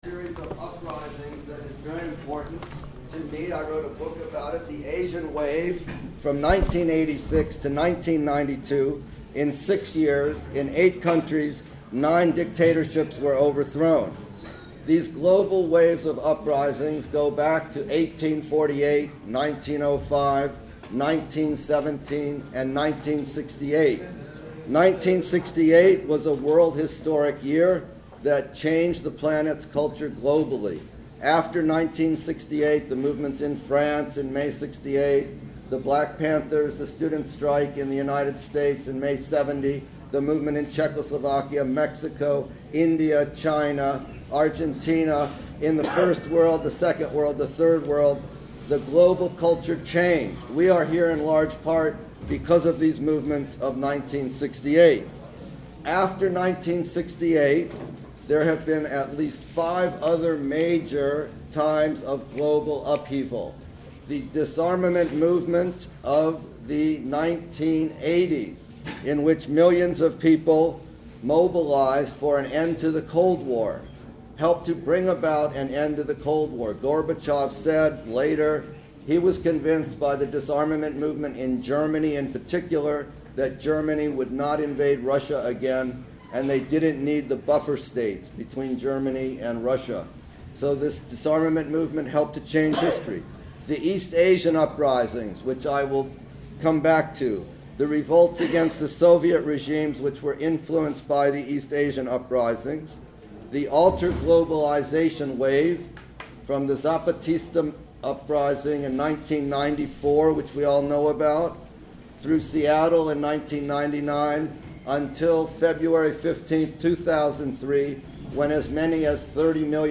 Audio of his comments (mp3, 50min, 50MB) and audience discussion (mp3, 1h10min, 64MB).
assange-discussion.mp3